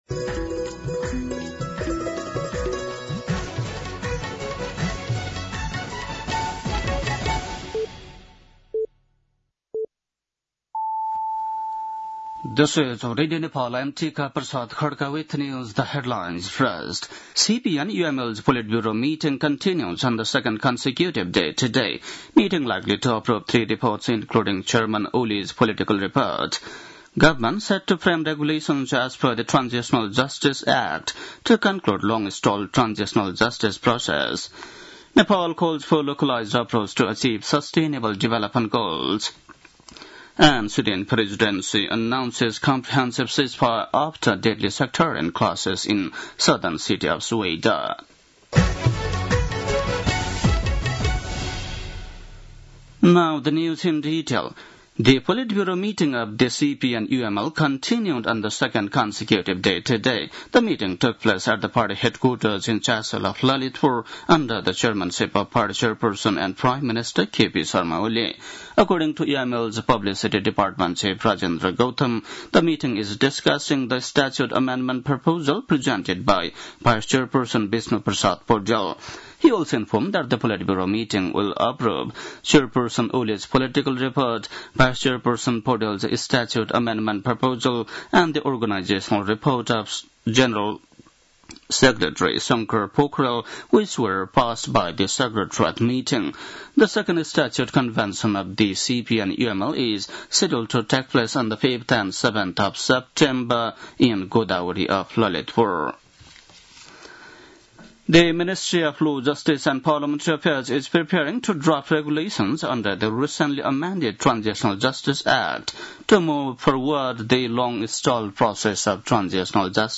बेलुकी ८ बजेको अङ्ग्रेजी समाचार : ३ साउन , २०८२